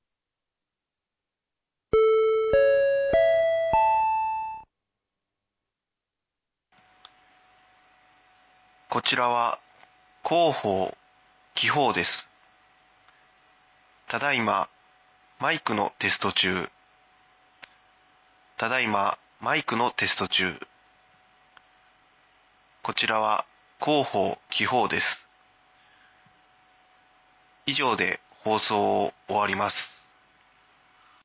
紀宝町防災無線情報
放送音声